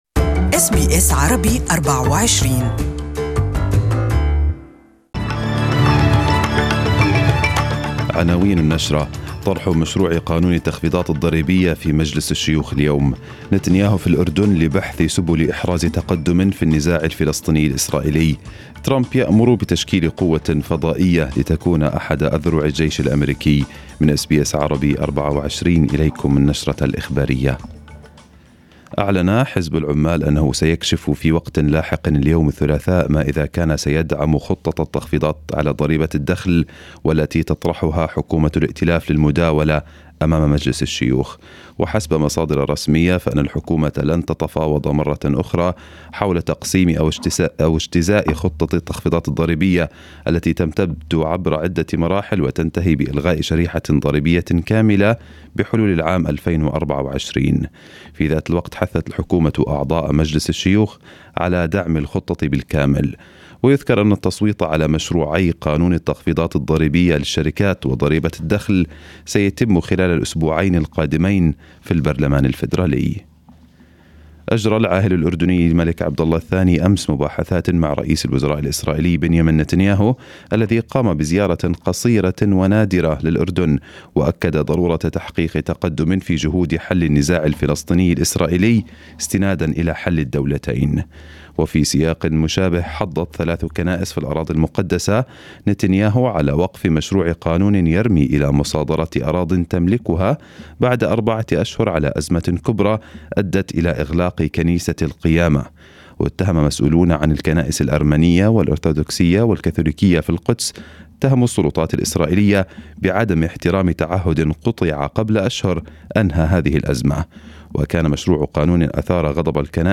News Bulletin from SBS Arabic 24